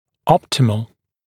[‘ɔptɪməl][‘оптимэл]оптимальный